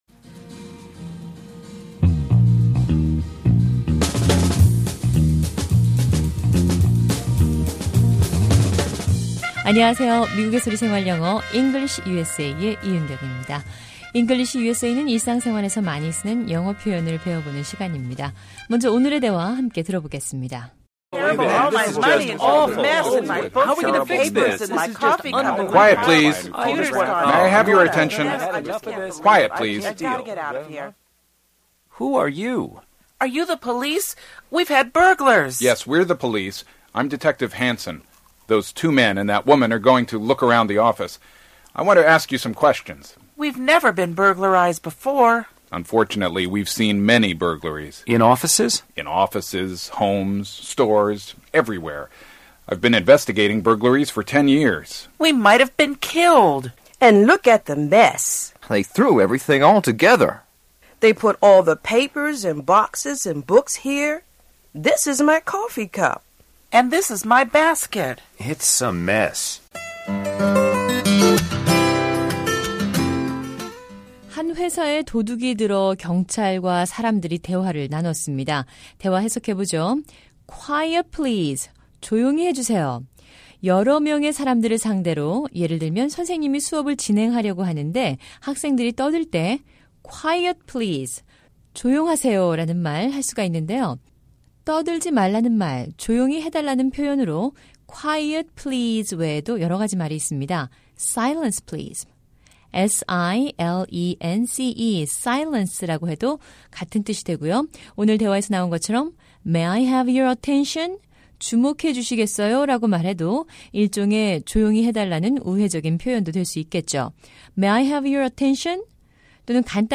English USA는 일상 생활에서 많이 쓰는 영어 표현을 배워보는 시간입니다. 오늘은 한 회사에 도둑이 들어 경찰과 사람들이 나누는 대화를 들어봅니다.